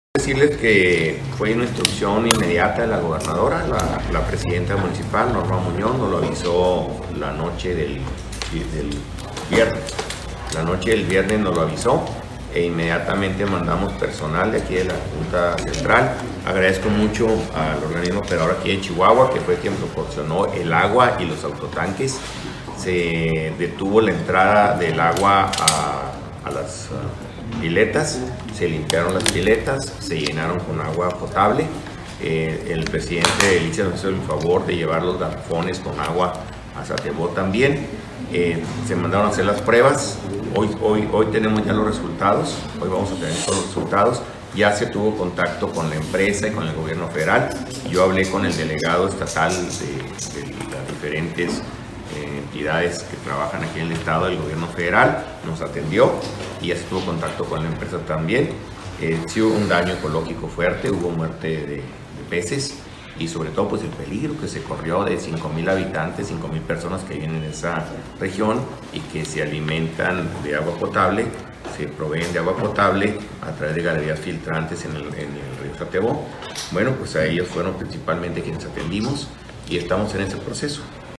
AUDIO: MARIO MATA CARRASCO, DIRECTOR EJECUTIVO DE LA JUNTA CENTRAL DE AGUA Y SANEAMIENTO (JCAS)